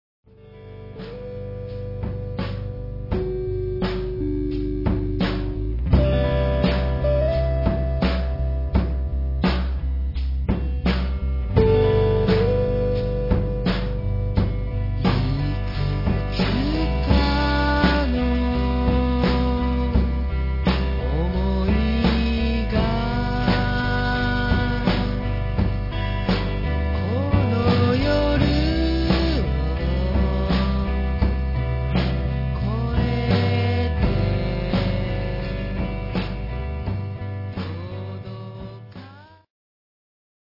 コメント 好評のジャパニーズ・サイケデリックバンド・サンプラー第三弾！